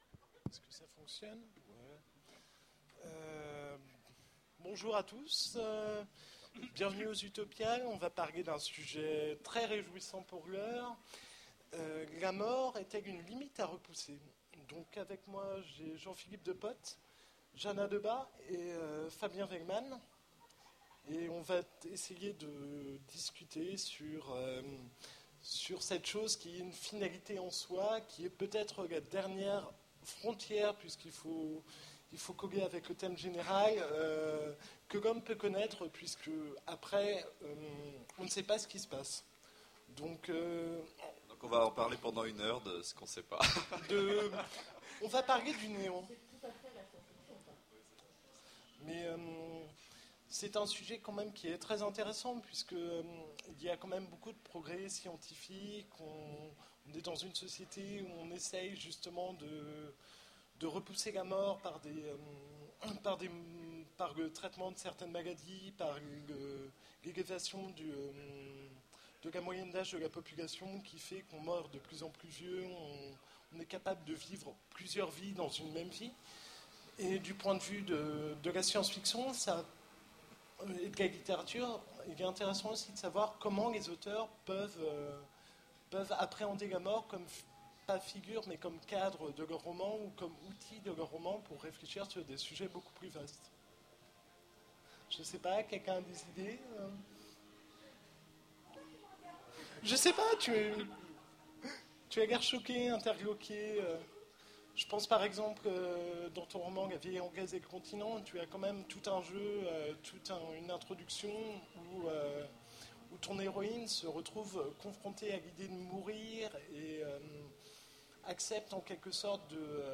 Utopiales 2010 : Conférence La Mort, une limite à repousser ?